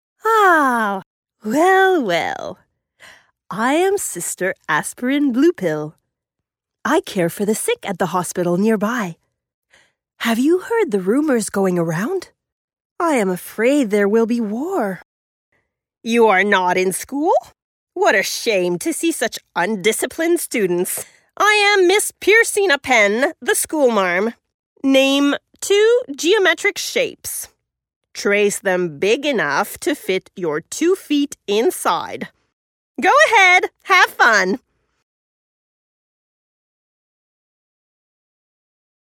Animation - EN